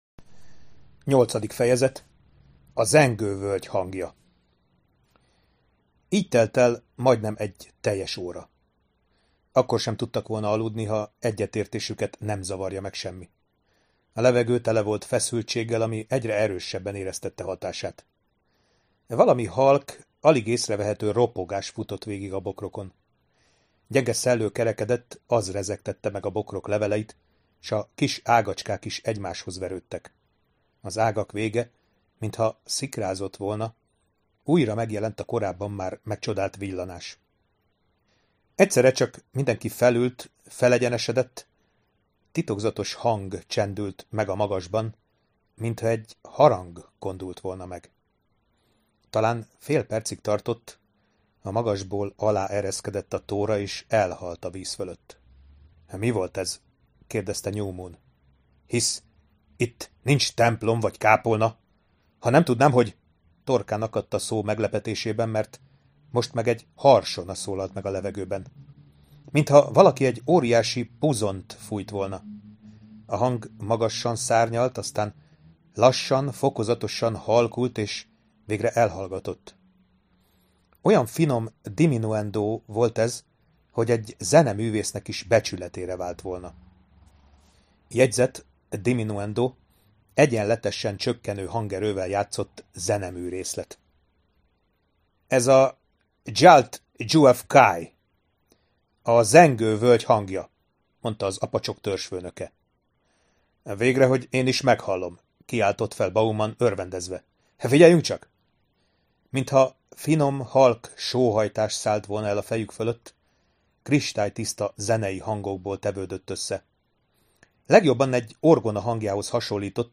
Hangoskönyvek